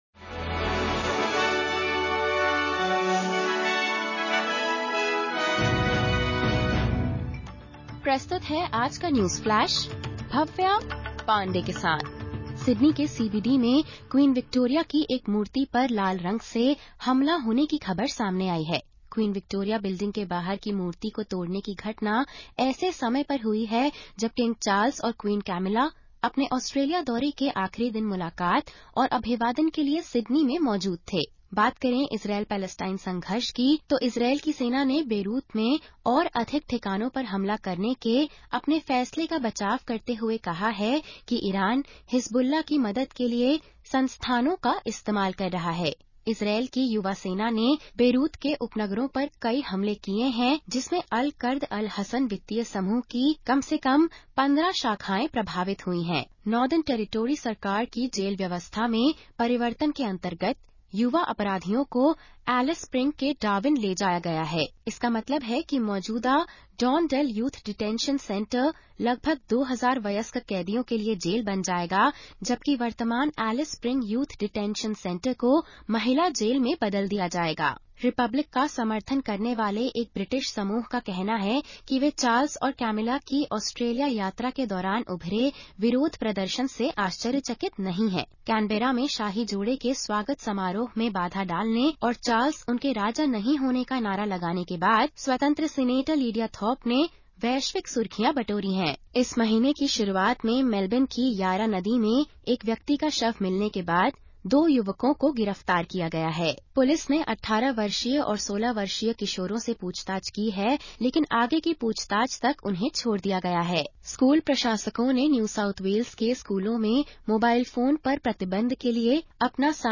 Listen to the top News from Australia in Hindi.